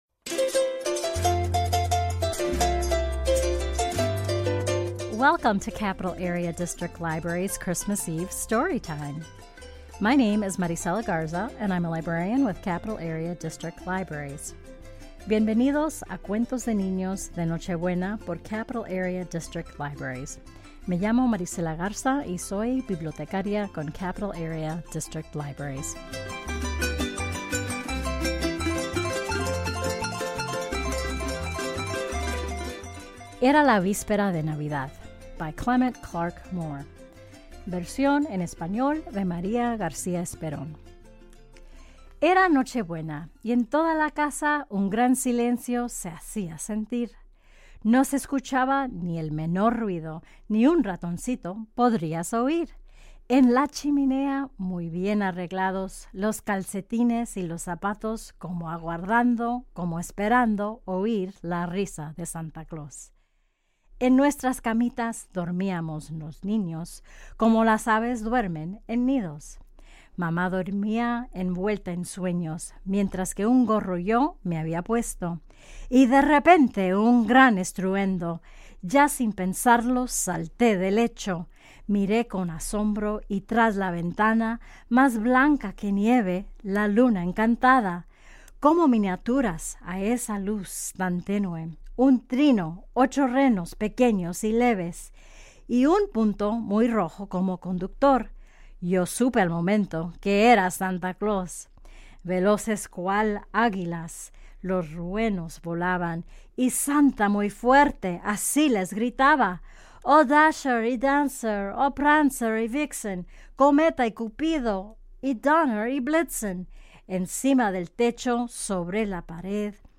Christmas Eve Radio Storytime (Spanish version) 2022